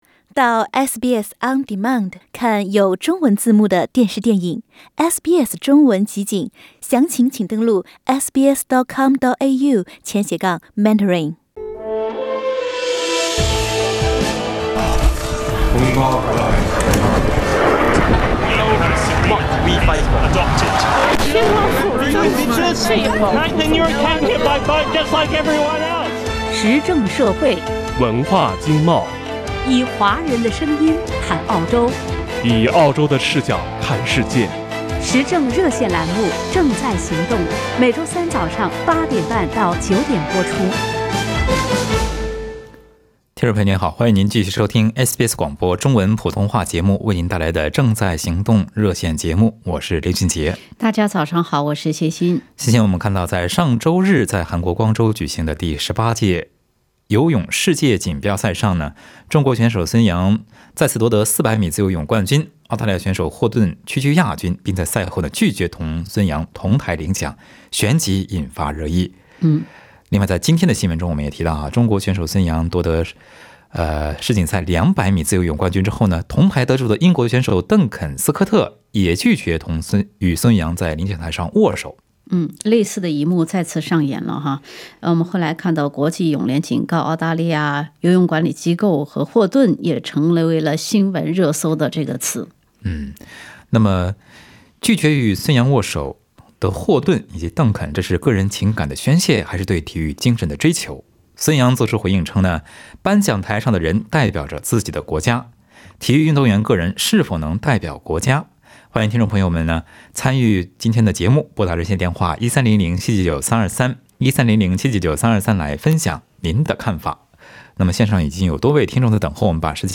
听众朋友们在本期《正在行动》节目中就此展开了讨论。